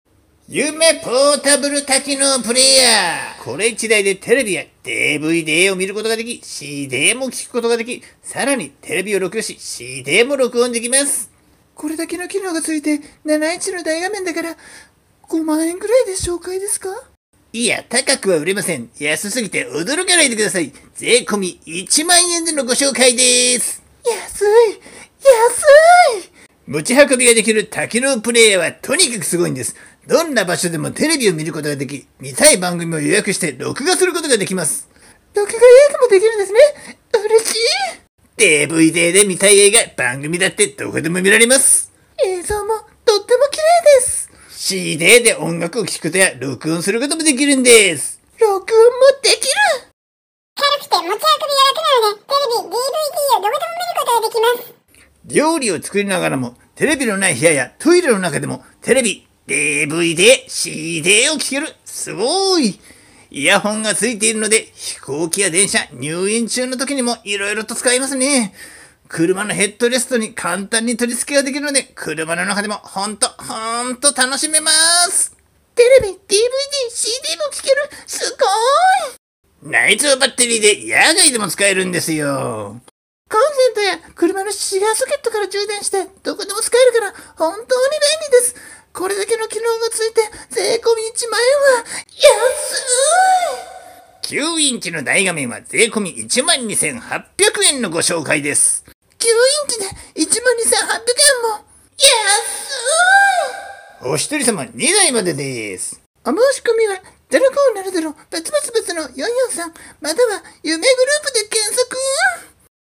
【声劇？🤣】夢ポータブル多機能プレーヤーCM